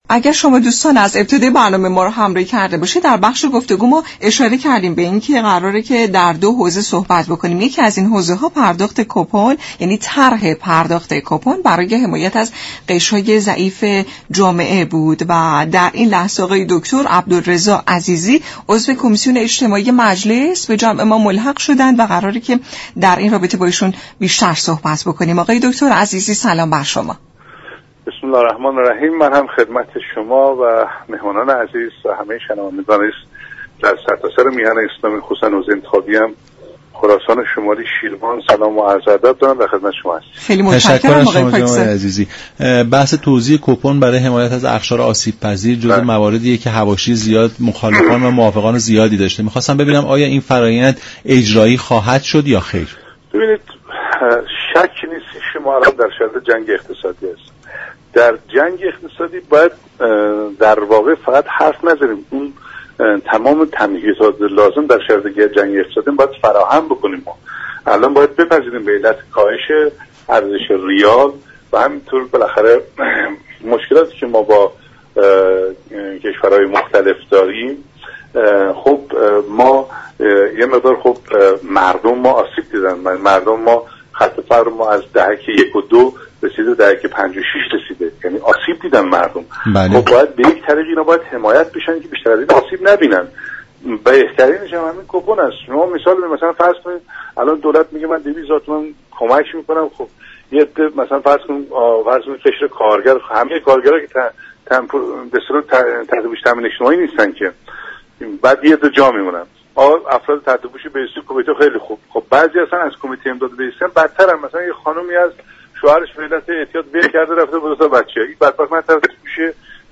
عضو كمیسیون اجتماعی مجلس شورای اسلامی در گفت و گو با رادیو ایران گفت: مجلس برای تامین كالاهای اساسی مردم 14 میلیارد دلار هزینه كرده است و با طرح كوپن دولت می تواند كالاهای اساسی را به شكلی عادلانه تر در دسترس مردم قرار دهد.